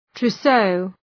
Προφορά
{‘tru:səʋ}